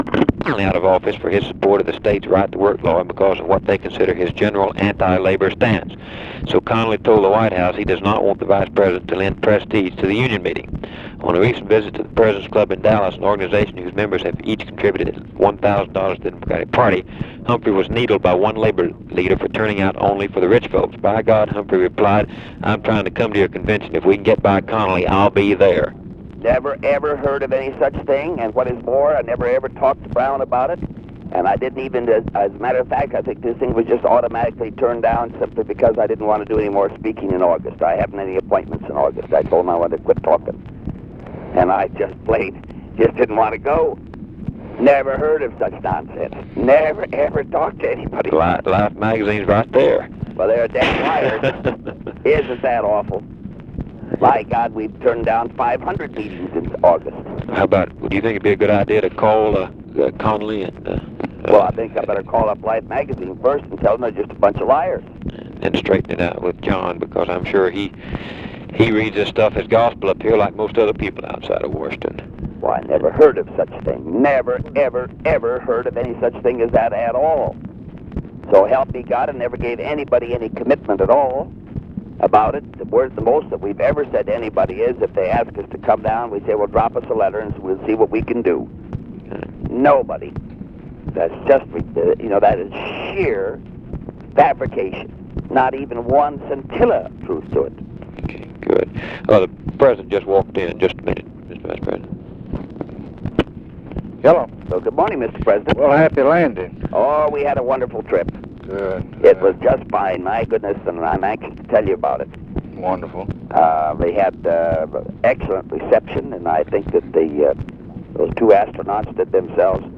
Conversation with HUBERT HUMPHREY and BILL MOYERS
Secret White House Tapes